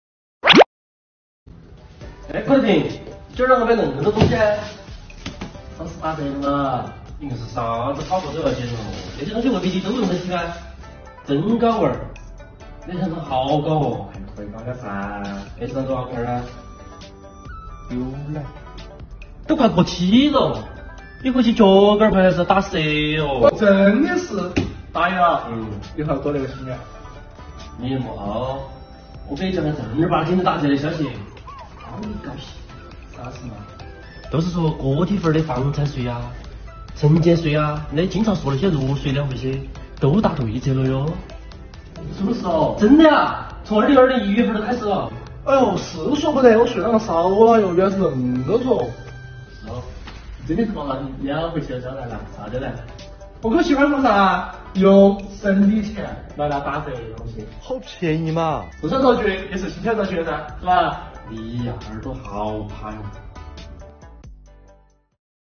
重庆言子来了② | 六税两费优惠政策您享受到了吗？